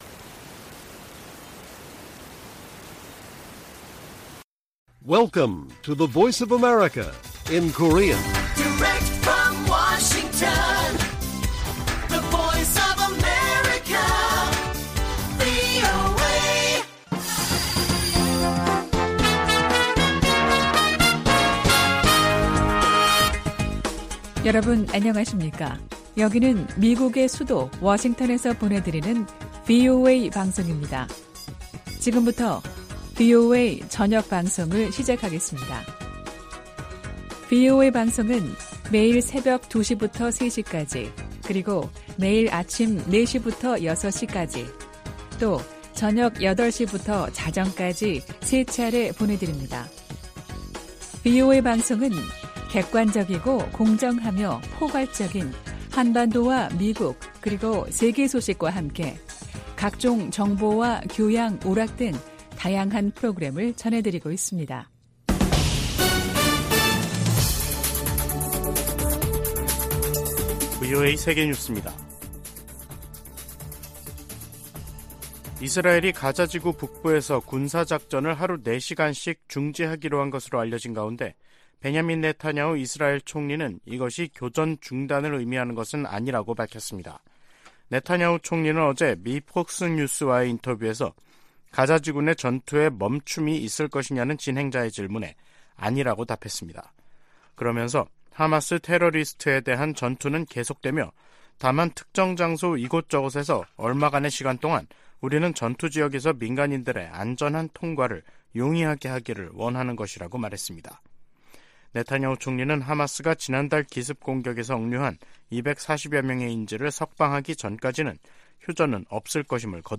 VOA 한국어 간판 뉴스 프로그램 '뉴스 투데이', 2023년 11월 11일 1부 방송입니다. 토니 블링컨 미국 국무장관이 윤석열 한국 대통령과 만나 북한과 우크라이나, 가자지구 문제 등 양국 현안을 논의했다고 국무부가 밝혔습니다. 미국 정부가 북한을 비호하는 러시아의 태도를 비판하면서 북한 정권에 분명한 메시지를 전할 것을 촉구했습니다.